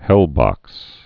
(hĕlbŏks)